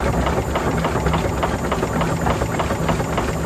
Steam Motor Short Loop Near End